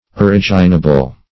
originable - definition of originable - synonyms, pronunciation, spelling from Free Dictionary
Originable \O*rig"i*na*ble\, a.